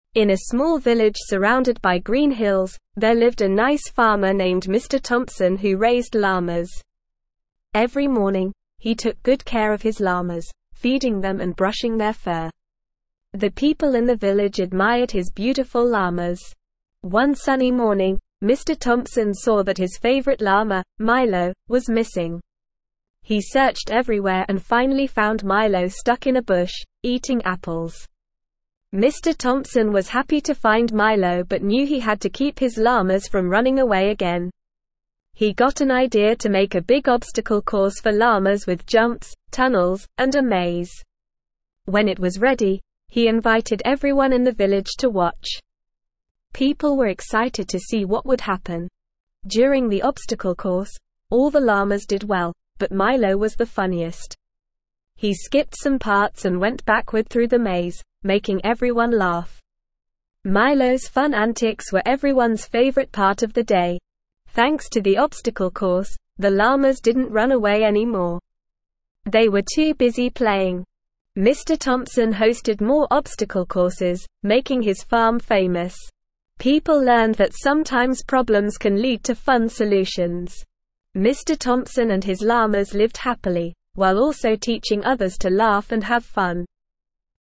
Normal
ESL-Short-Stories-for-Kids-Lower-Intermediate-NORMAL-Reading-The-Llama-Farmer.mp3